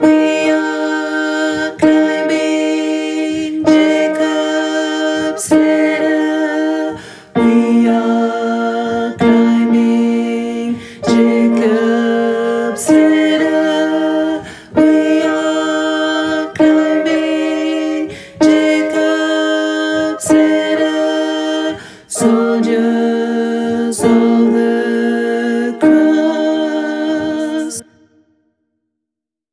Basse et autres voix en arrière-plan